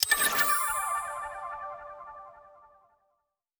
bonus_buy_popup.wav